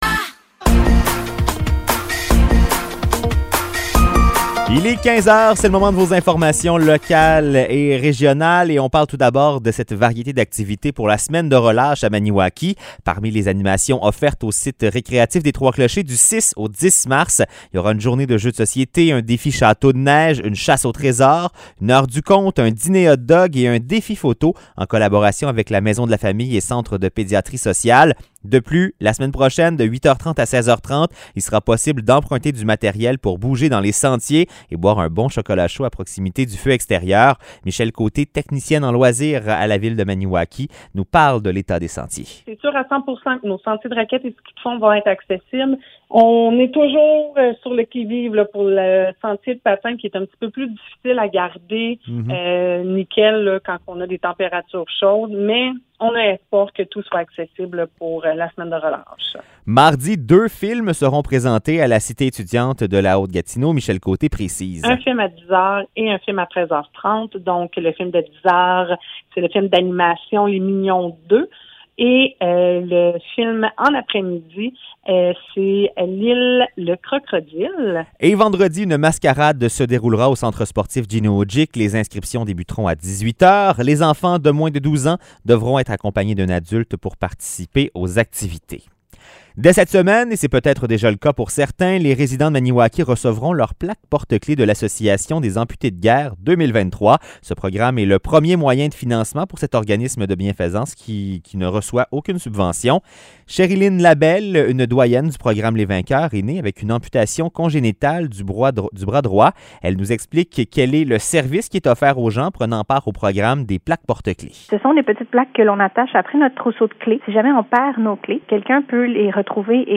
Nouvelles locales - 3 mars 2023 - 15 h